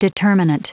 Prononciation du mot determinant en anglais (fichier audio)
Prononciation du mot : determinant